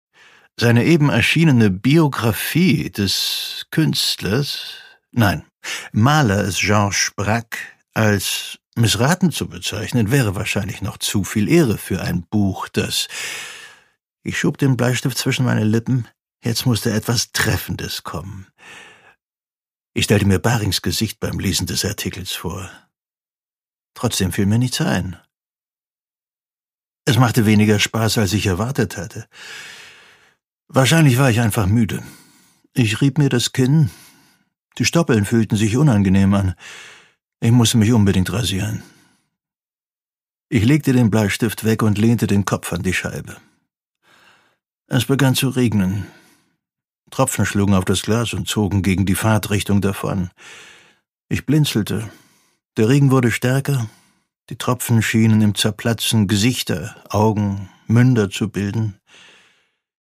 Produkttyp: Hörbuch-Download
Gelesen von: Ulrich Noethen